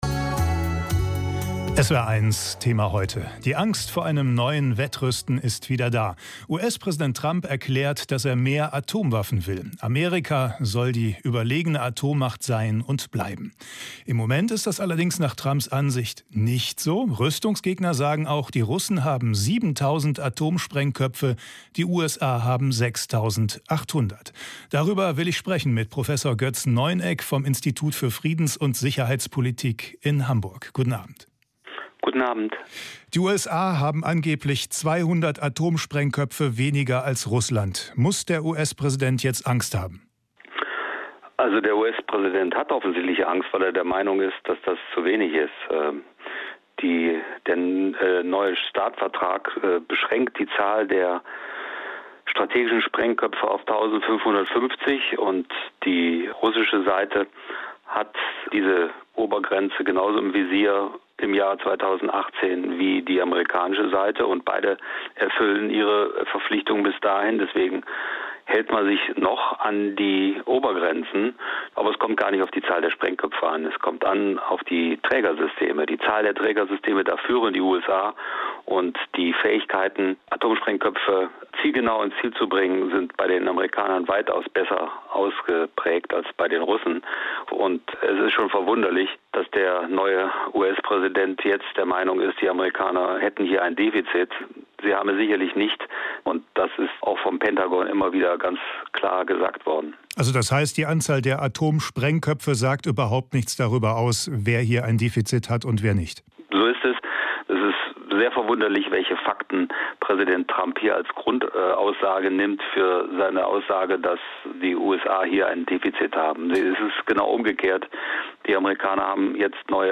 Interviews zu Trumps Atomplänen